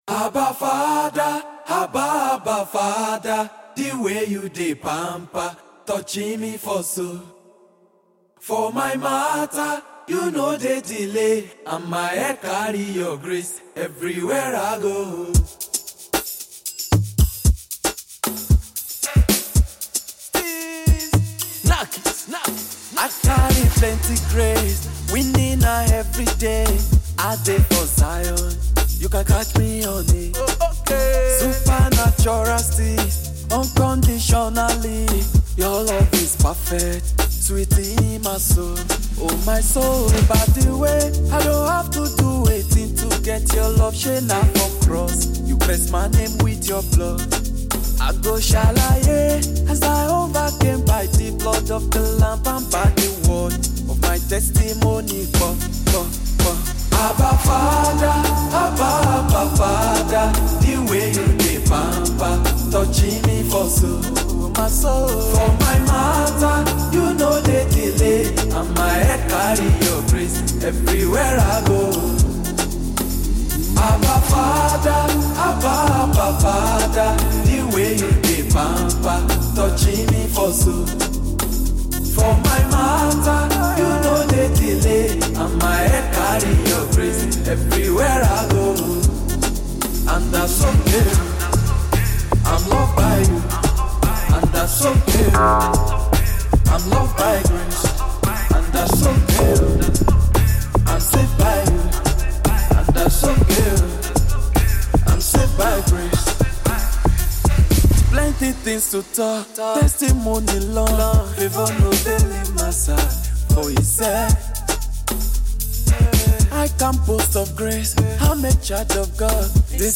The newly released Afro-gospel song